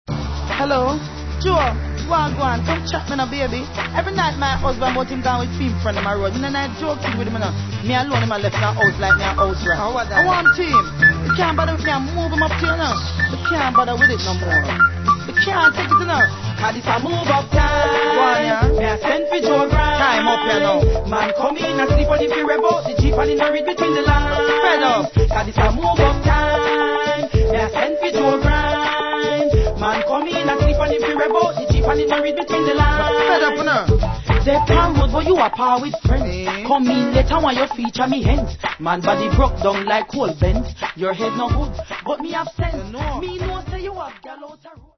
TOP 10 DANCEHALL